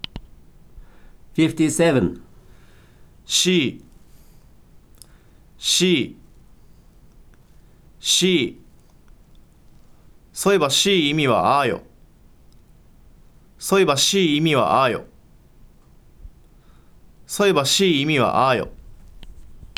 If you click the word in a celll in the table, then you can hear the `non-past' form of the verb and a sentence containing the `non-past' form as the verb of the adnominal clause in Saga western dialect.